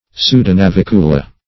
Search Result for " pseudonavicula" : The Collaborative International Dictionary of English v.0.48: Pseudonavicula \Pseu`do*na*vic"u*la\, n.; pl.
pseudonavicula.mp3